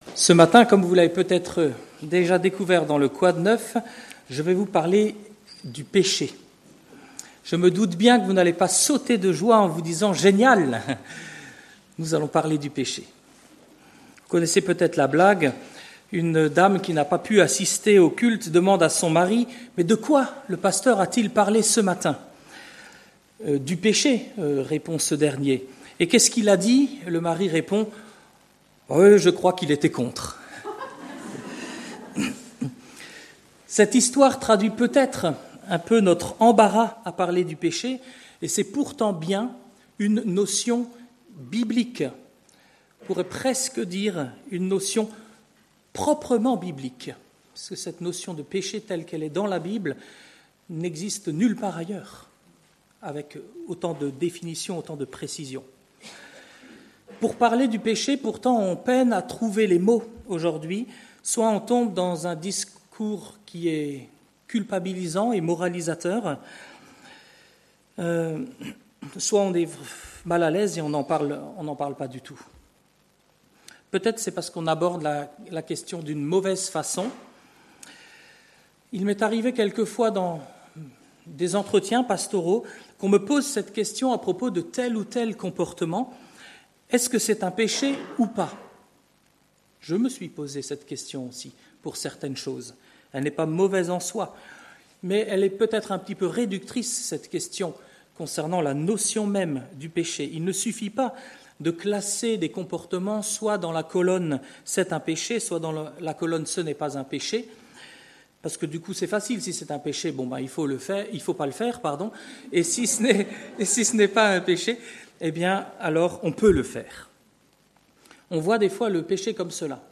Culte du dimanche 24 novembre 2024 – Église de La Bonne Nouvelle